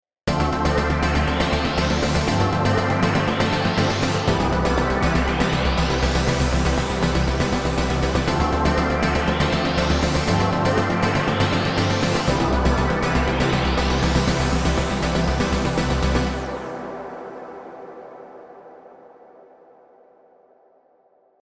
このパートとエフェクトの音だけを抜き出してみました。
奥行きのある深～い音になりましたね。